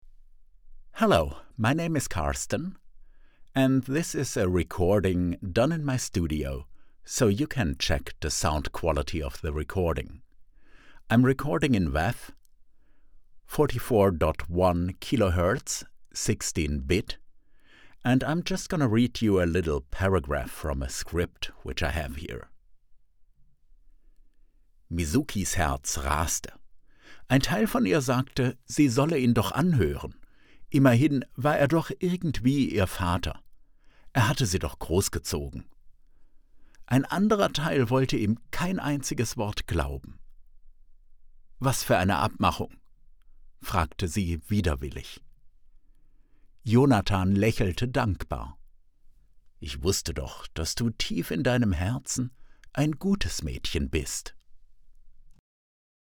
Male
Approachable, Authoritative, Character, Confident, Conversational, Cool, Corporate, Engaging, Friendly, Natural, Reassuring, Smooth, Versatile, Warm
Standard German, English with German/European accent
Microphone: Blue Bluebird SL